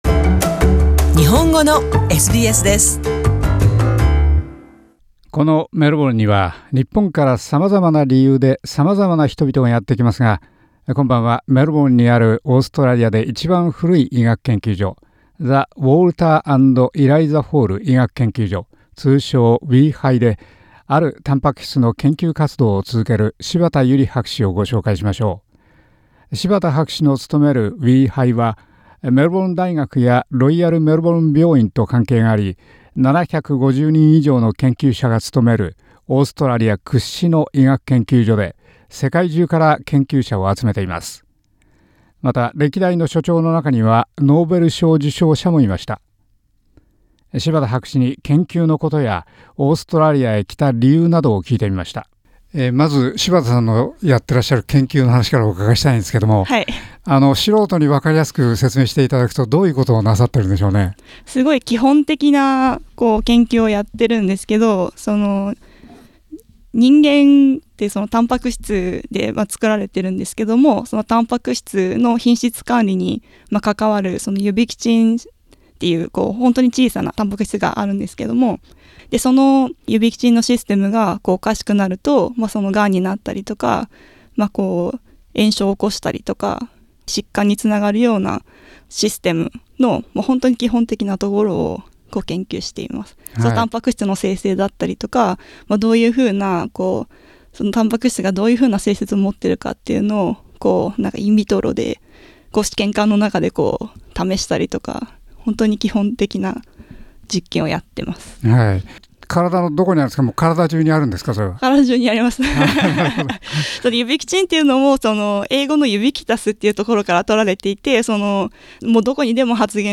博士に仕事のことやメルボルンでの生活などについて聞きました。